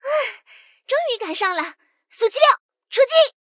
SU-76出击语音.OGG